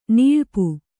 ♪ nilpu